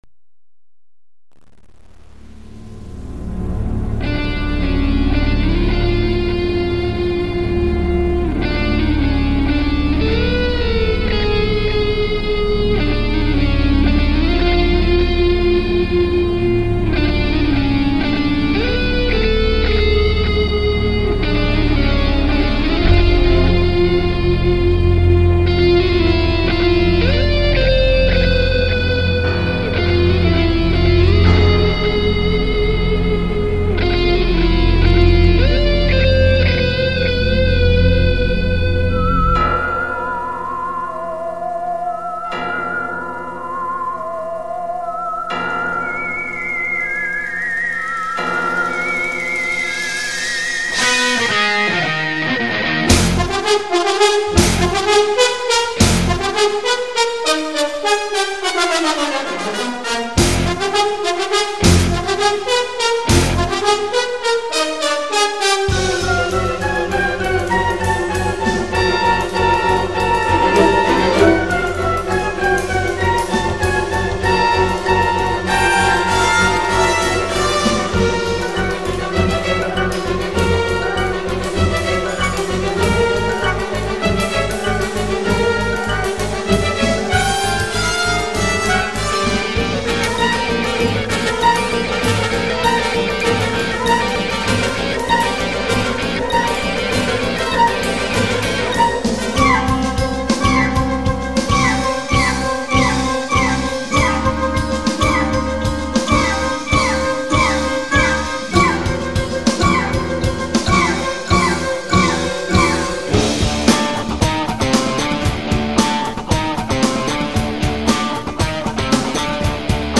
lead guitar